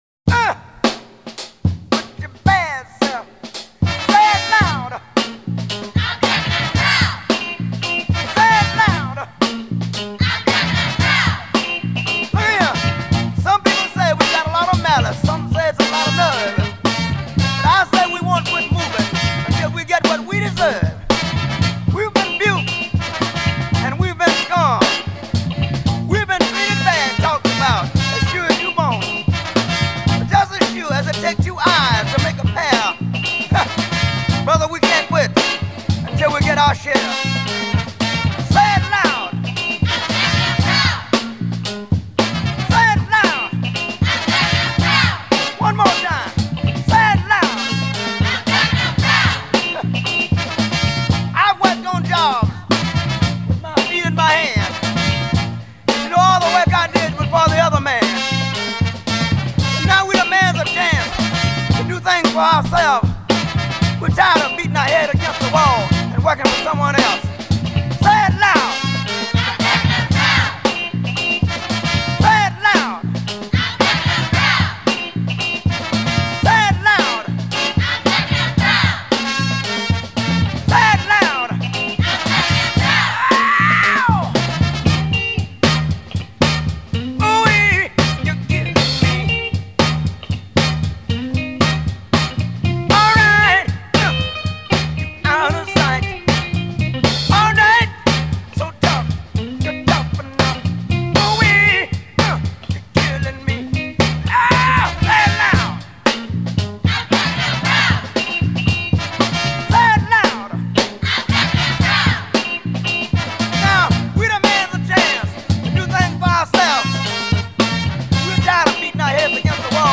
Funkmusikkens baggrund